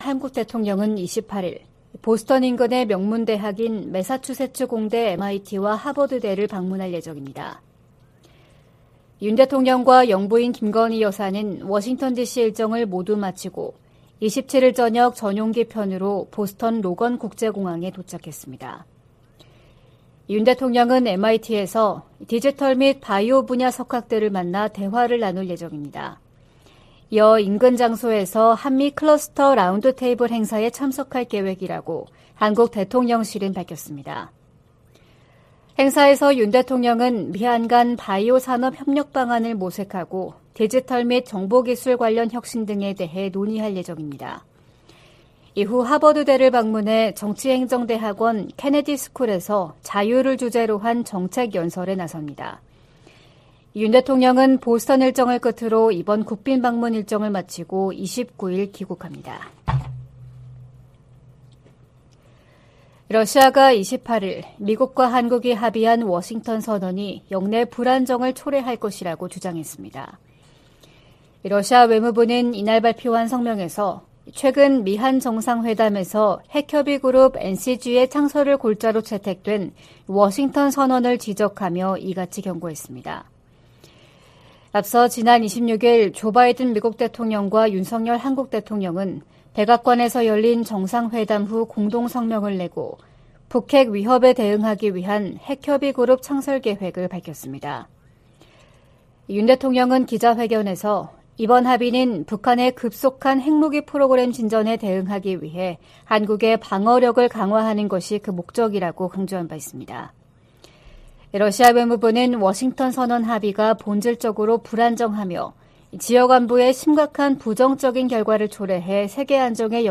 VOA 한국어 '출발 뉴스 쇼', 2023년 4월 29일 방송입니다. 윤석열 한국 대통령은 27일 미 상·하원 합동회의 연설에서 북한 도발에 단호히 대응할 것이라며 미한 공조와 미한일 협력의 중요성을 강조했습니다. 국무부 오찬에 참석한 윤 대통령은 미한 동맹이 안보를 넘어 다양한 분야에서 강화되고 있다고 평가했습니다. 미국의 전문가들은 윤 대통령이 조 바이든 미국 대통령과 함께 동맹의 미래 청사진을 제시했다고 평가했습니다.